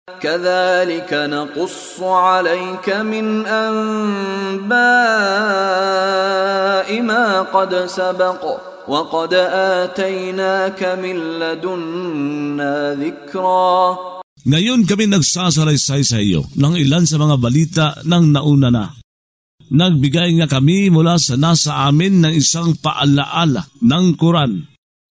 Pagbabasa ng audio sa Filipino (Tagalog) ng mga kahulugan ng Surah Ta Ha ( Mga Titik Ta at Ha ) na hinati sa mga taludtod, na sinasabayan ng pagbigkas ng reciter na si Mishari bin Rashid Al-Afasy. Ang kaligayahan dahilsa pagsunod sa patnubay ng Qur'ān atpagdala ng mensahenito at ang kalumbayan dahil sa pagsalungatdito.